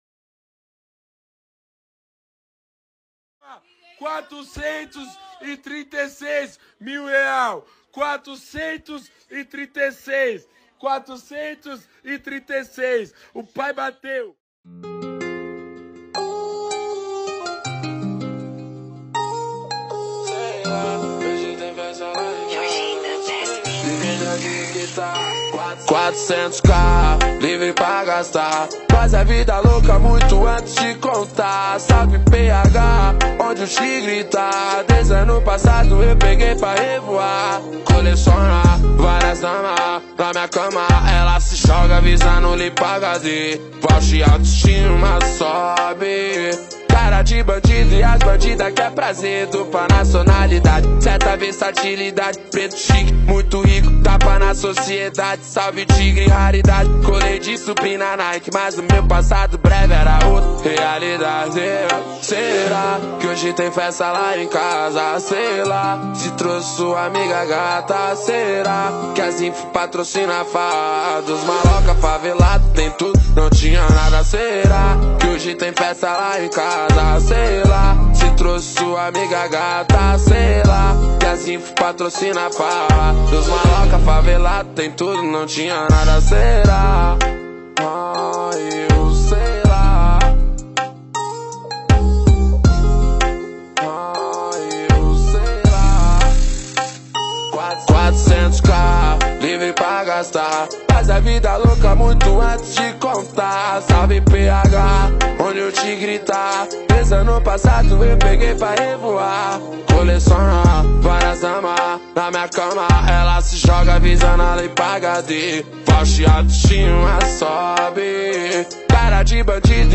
2024-02-24 03:12:42 Gênero: Funk Views